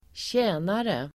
Uttal: [²tj'ä:nare]